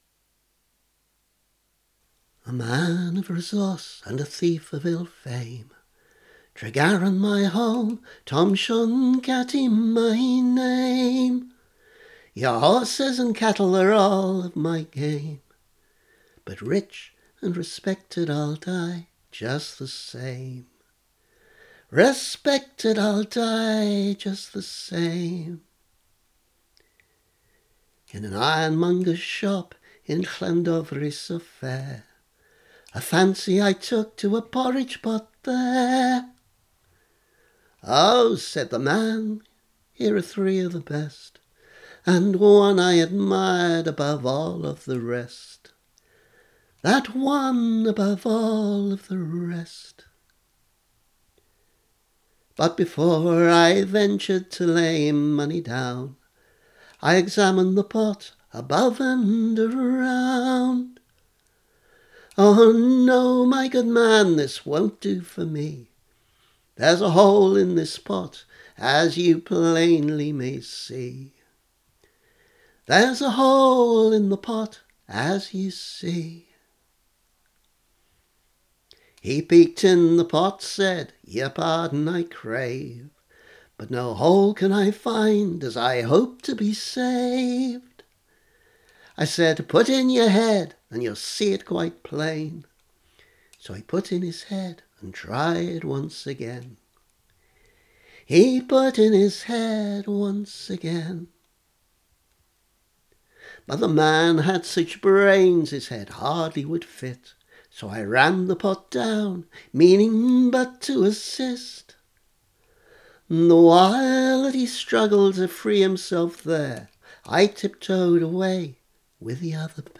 Three traditional tunes for the price of one